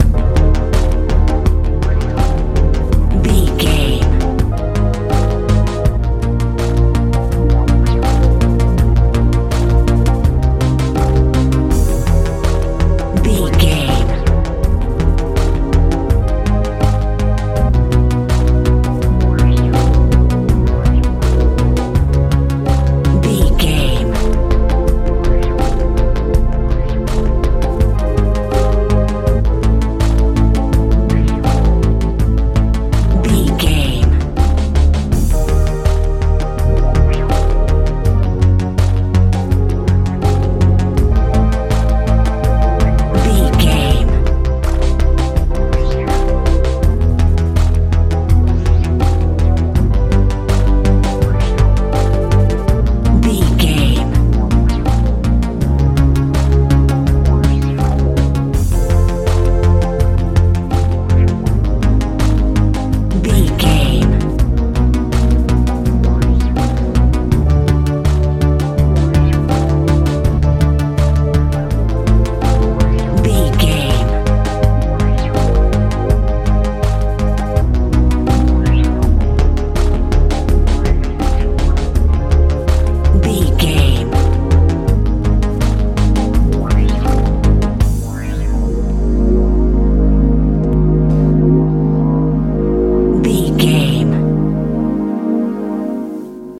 techno
Aeolian/Minor
D
magical
mystical
synthesiser
bass guitar
drums
80s
suspense
strange